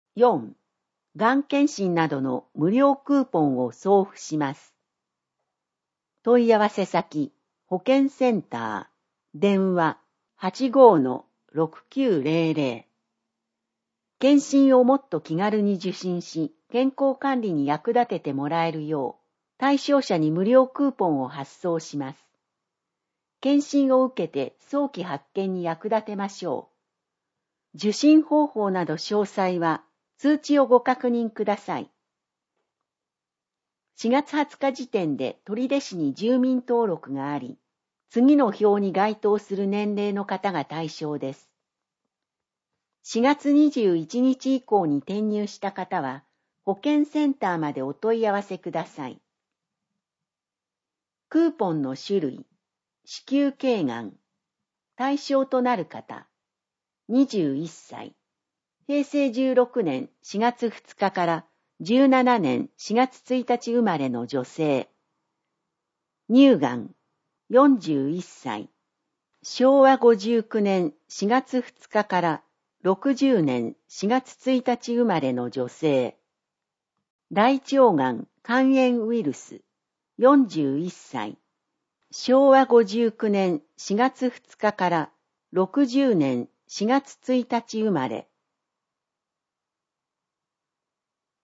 取手市の市報「広報とりで」2025年5月1日号の内容を音声で聞くことができます。音声データは市内のボランティア団体、取手朗読奉仕会「ぶんぶん」の皆さんのご協力により作成しています。